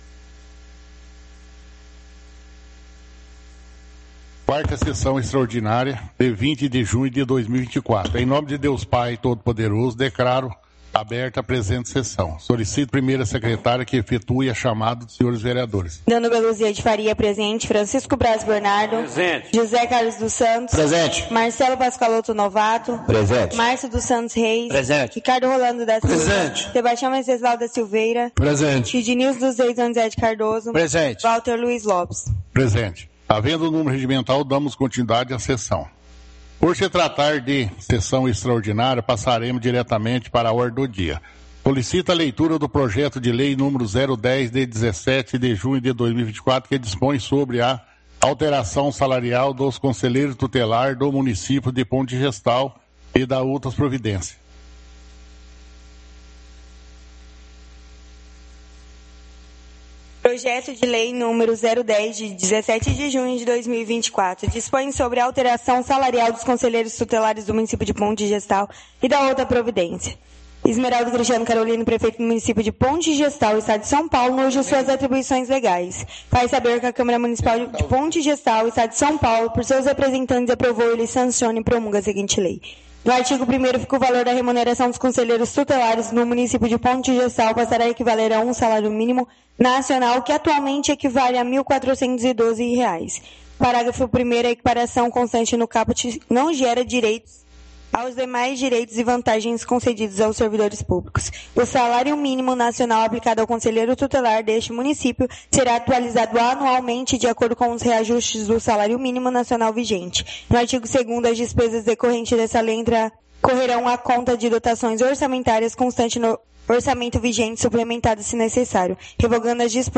Áudio da 4ª Sessão Extraordinária – 20/06/2024